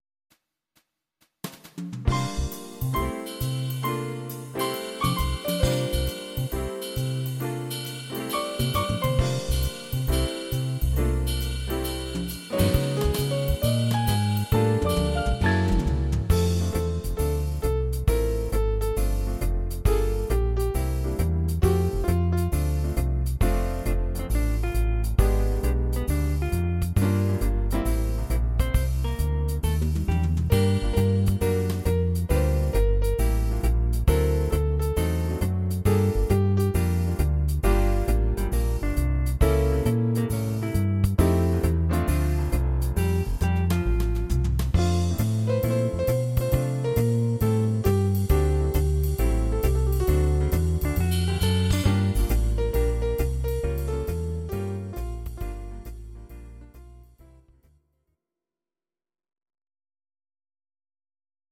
These are MP3 versions of our MIDI file catalogue.
Please note: no vocals and no karaoke included.
Bar Piano